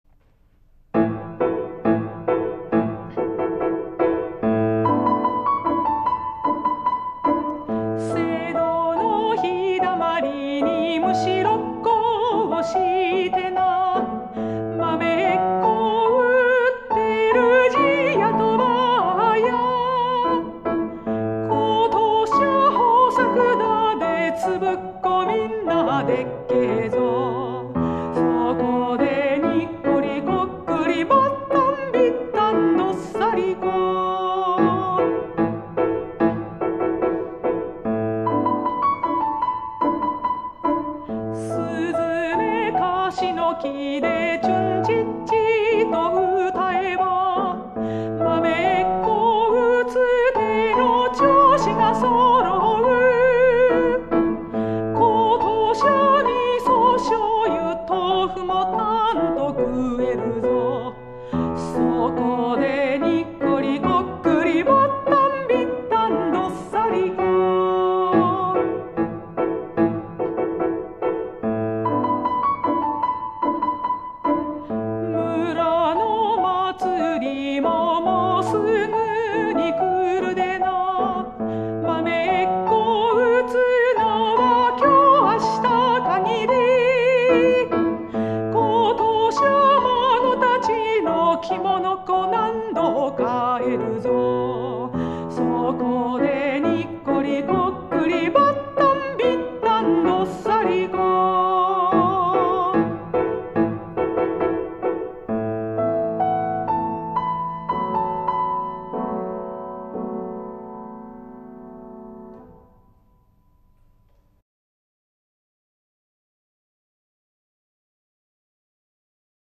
メゾ・ソプラノ
ピアノ